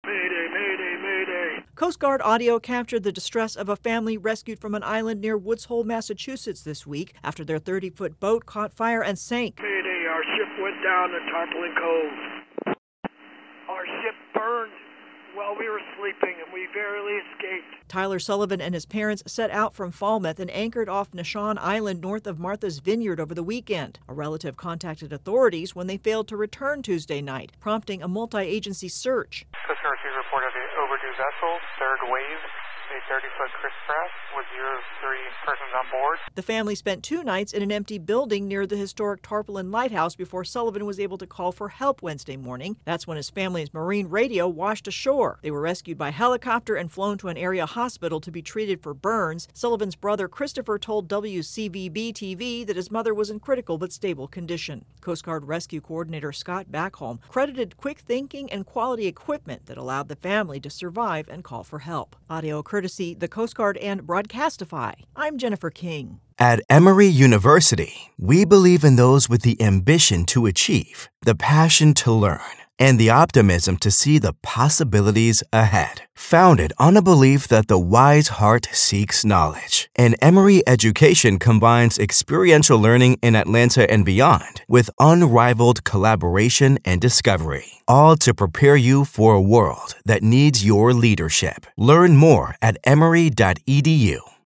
Coast Guard audio captures stranded family's mayday call. 'Our ship burned while we were sleeping'